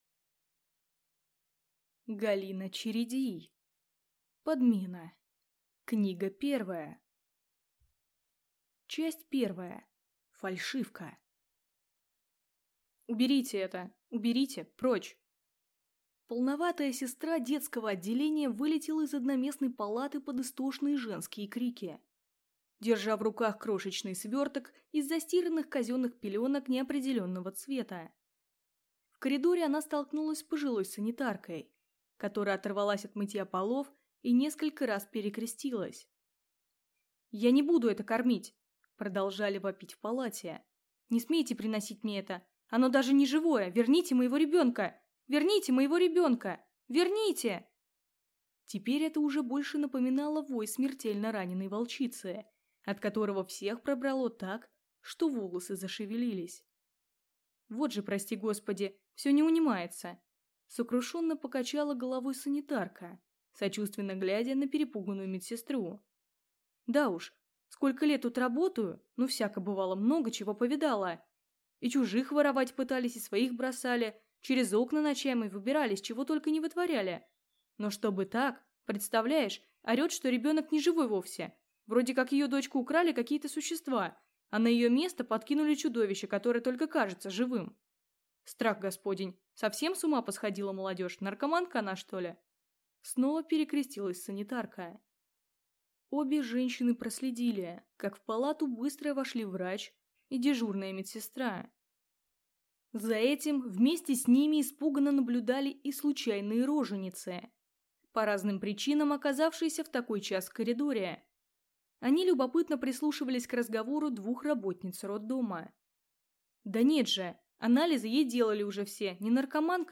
Аудиокнига Подмена | Библиотека аудиокниг